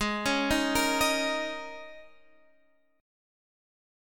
G#sus2sus4 chord